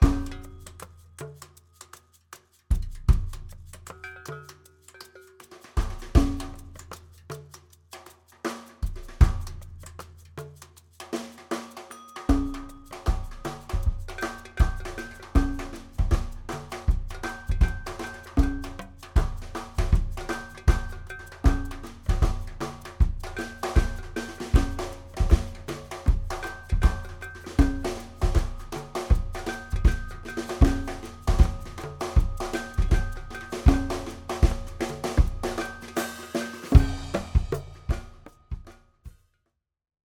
Percussive+cajon+thang.m4a